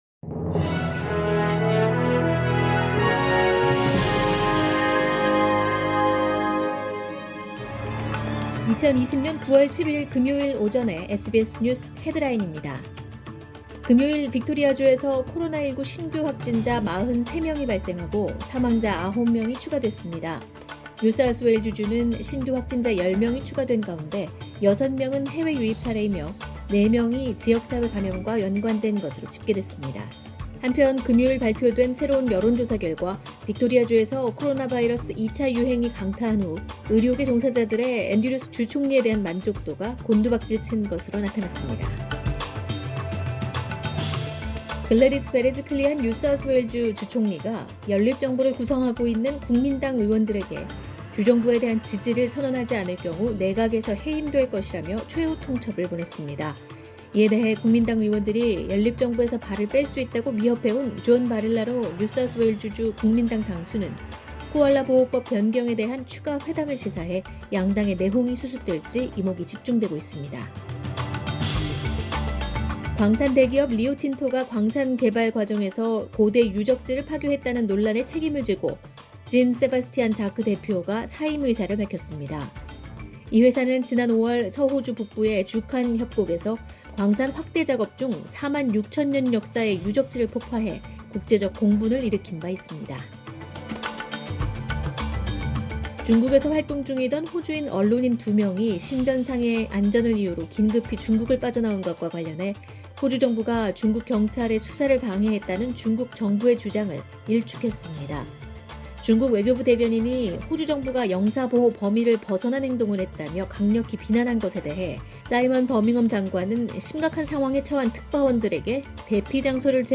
2020년 9월 11일 금요일 오전의 SBS 뉴스 헤드라인입니다.